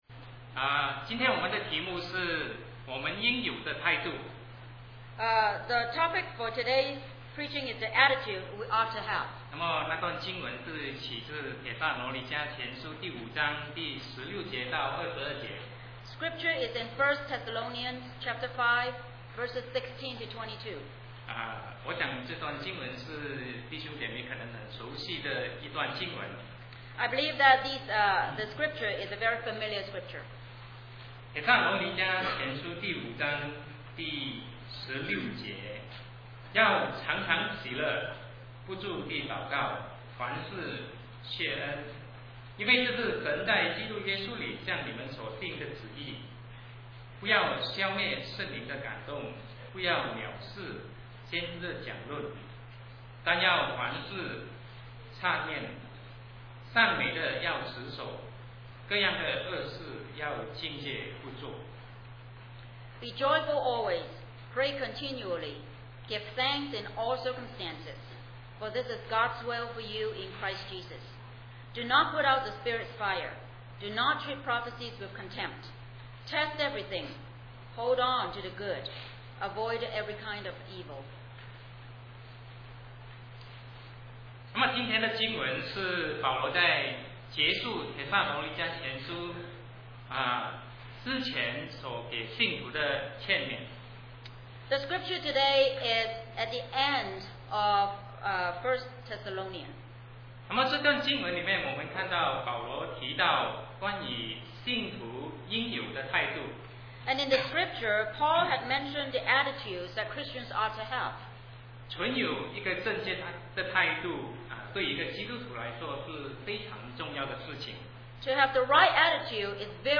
Sermon 2007-07-01 The Attitudes We Ought to Have